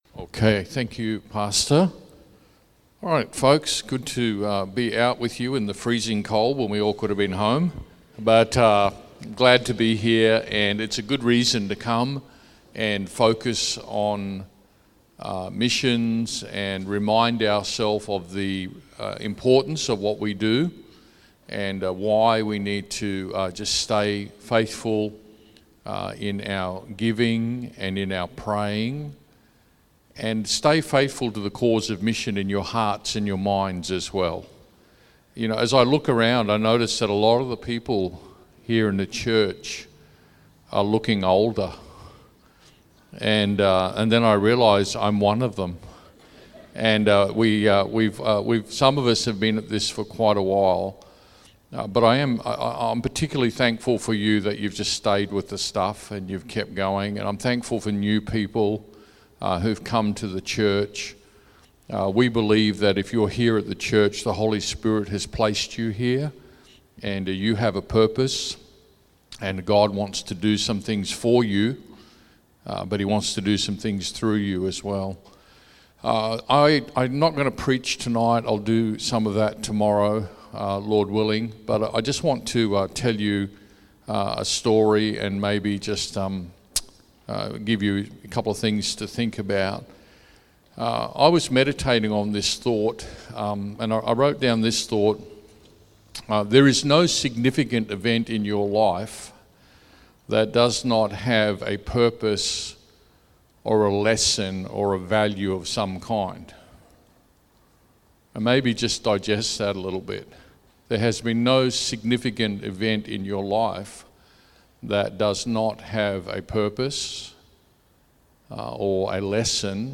Sermons | Good Shepherd Baptist Church
Sat AM Missions Conference 2022